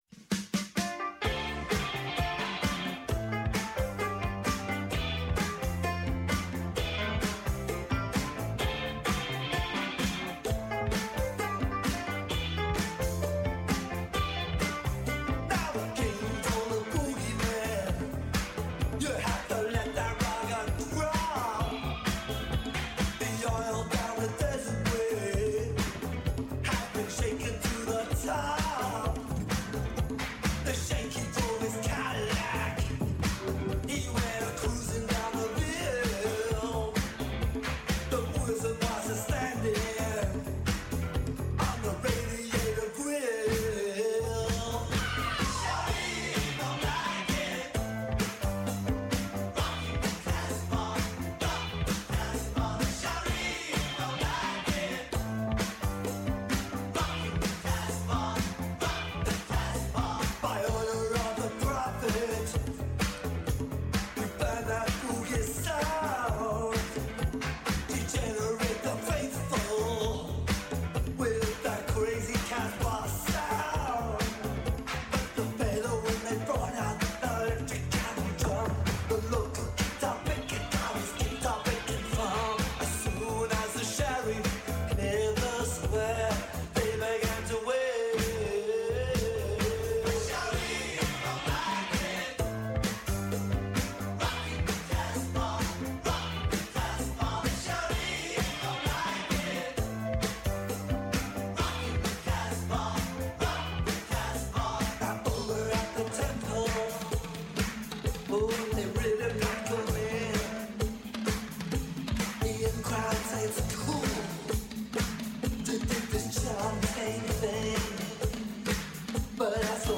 Άνθρωποι της επιστήμης, της ακαδημαϊκής κοινότητας, πολιτικοί, ευρωβουλευτές, εκπρόσωποι Μη Κυβερνητικών Οργανώσεων και της Κοινωνίας των Πολιτών συζητούν για όλα τα τρέχοντα και διηνεκή ζητήματα που απασχολούν τη ζωή όλων μας από την Ελλάδα και την Ευρώπη μέχρι την άκρη του κόσμου.